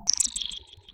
Grabcrab_putdown.ogg